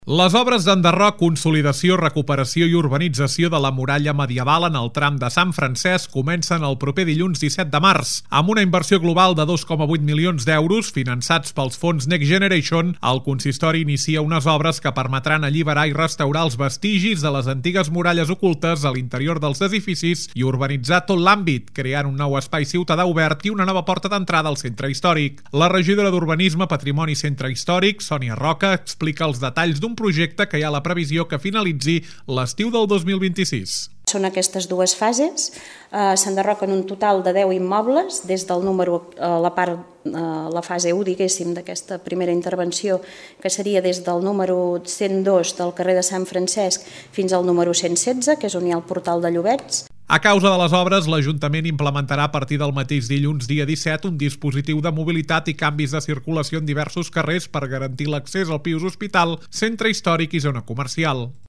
La regidora d’Urbanisme, Patrimoni i Centre Històric, Sònia Roca, explica els detalls d’un projecte que hi ha la previsió que finalitzi l’estiu del 2026.